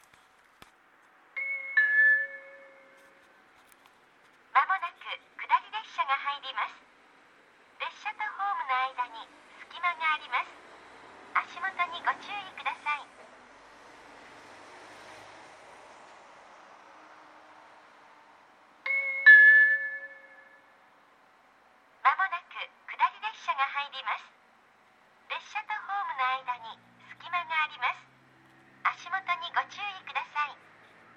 この駅では接近放送が設置されています。
接近放送普通　石巻行き接近放送です。